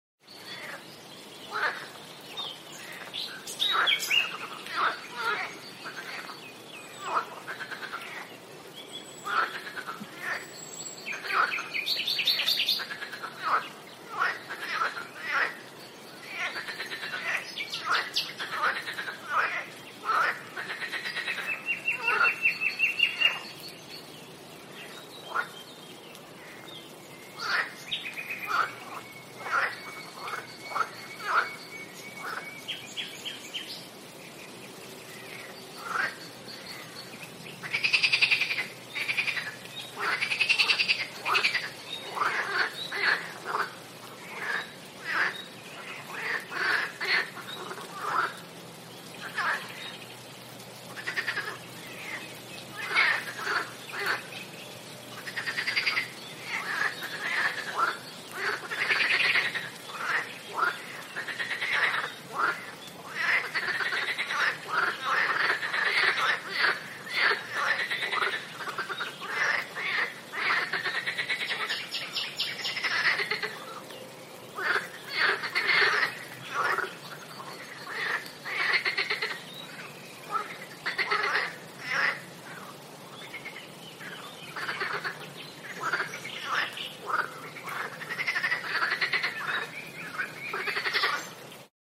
Âm thanh môi trường, tiếng động vật ở Đầm lầy, Ẩm ướt, Trong rừng…
Thể loại: Tiếng động
Description: Âm thanh môi trường, tiếng động vật ở Đầm lầy, Ẩm ướt, Trong rừng, Swamp Sounds, Animal Sounds Sound Effects...
am-thanh-moi-truong-tieng-dong-vat-o-dam-lay-am-uot-trong-rung-rung-www_tiengdong_com.mp3